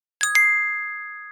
notify.mp3